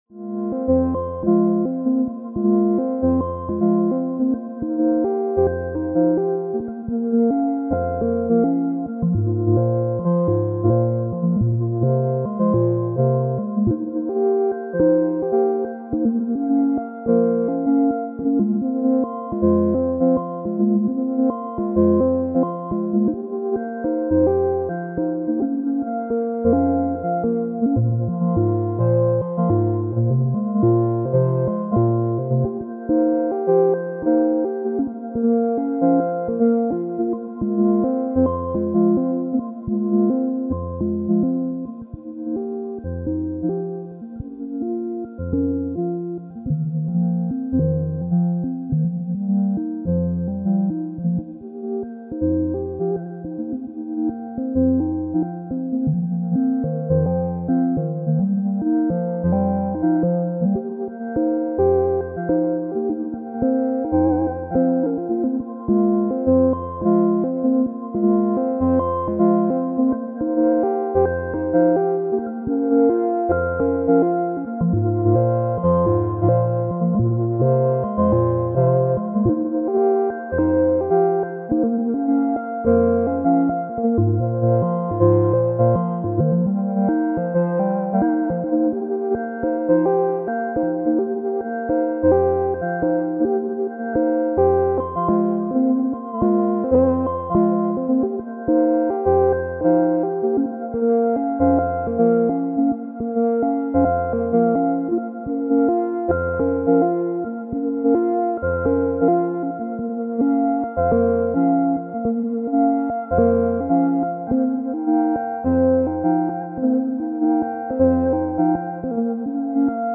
Some solo time with the Subharmonicon